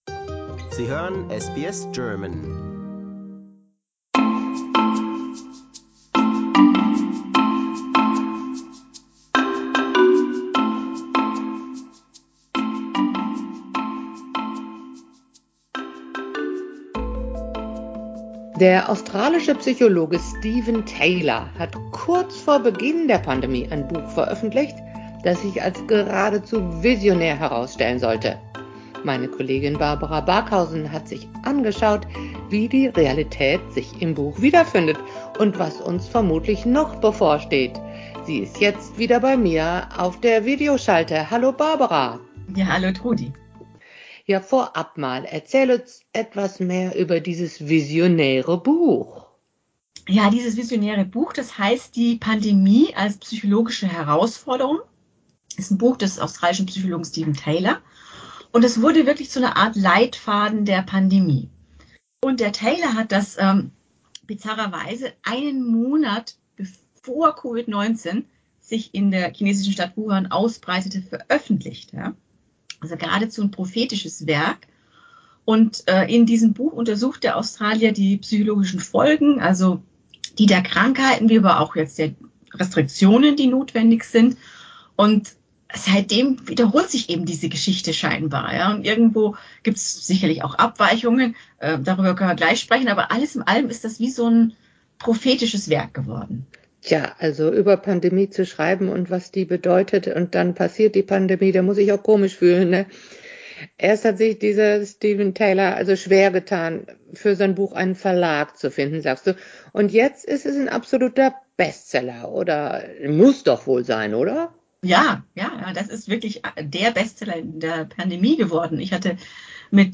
Sie sprach mit mir darüber in einem Videomeeting.